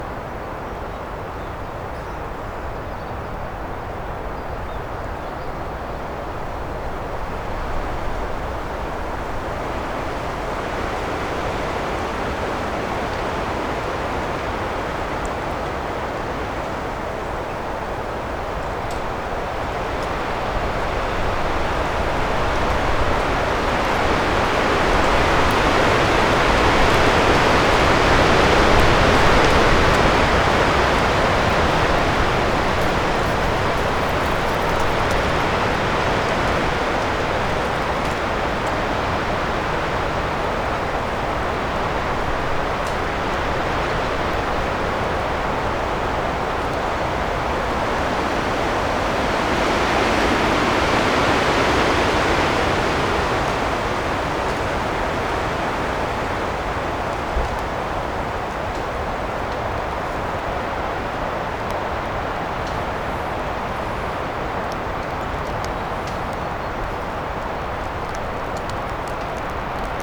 mandrake foundry13data/Data/modules/soundfxlibrary/Nature/Loops/Wind
gust of conifers
wind-1.mp3